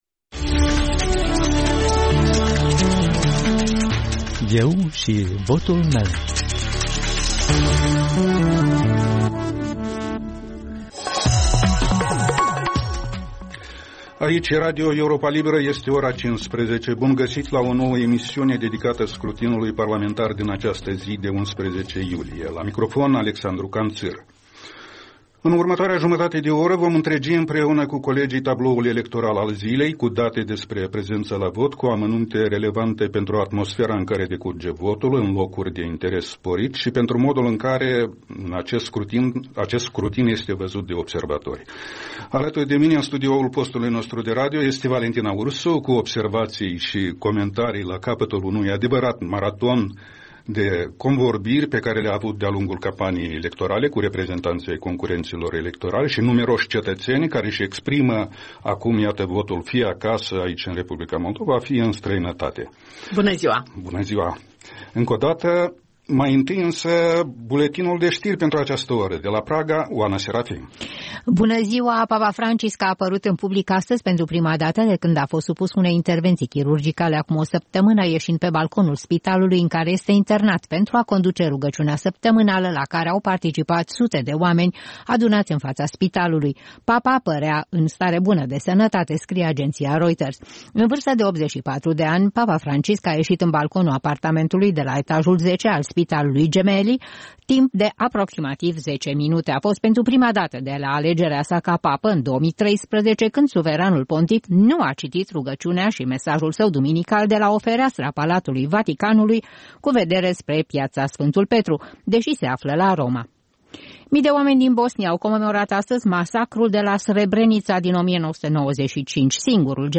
În duminica alegerilor parlamentare anticipate, Europa Liberă are programe electorale speciale. Reportaje, analize și voci din diaspora.